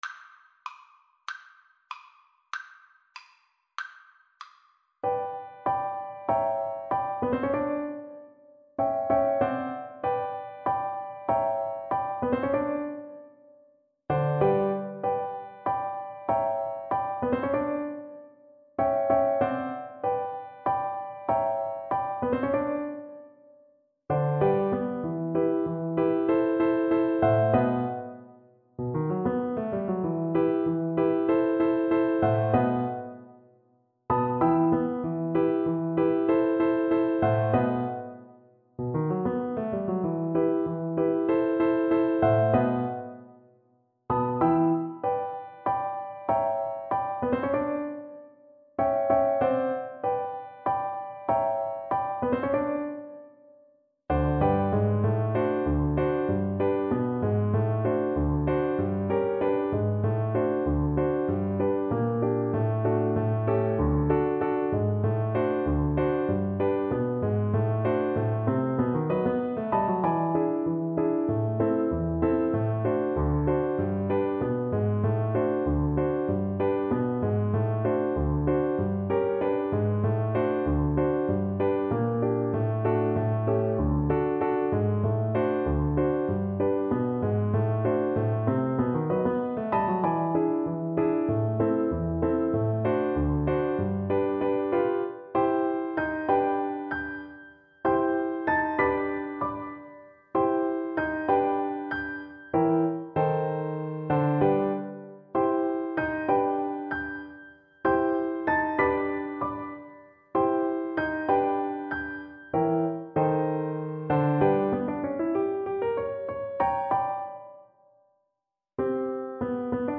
Play (or use space bar on your keyboard) Pause Music Playalong - Piano Accompaniment Playalong Band Accompaniment not yet available transpose reset tempo print settings full screen
Viola
G major (Sounding Pitch) (View more G major Music for Viola )
= 96 Fast or slow
2/4 (View more 2/4 Music)
Jazz (View more Jazz Viola Music)